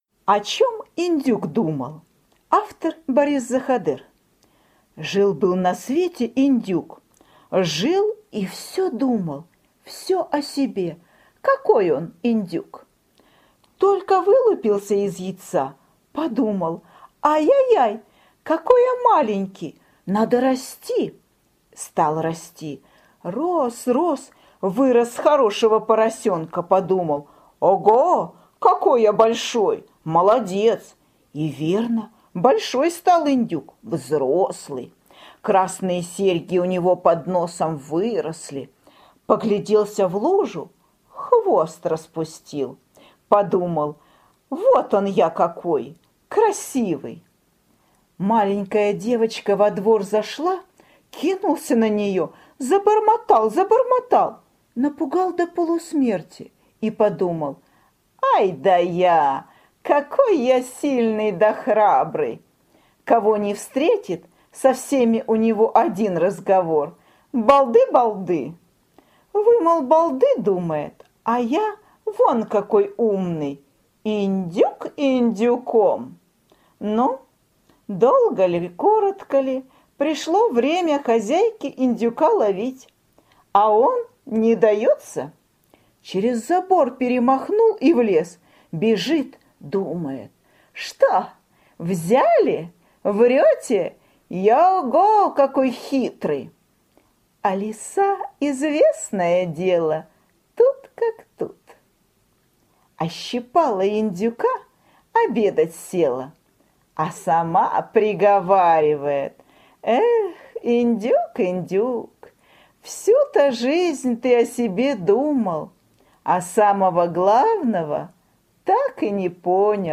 О чем индюк думал – Заходер Б.В. (аудиоверсия)
Аудиокнига в разделах